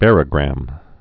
(bărə-grăm)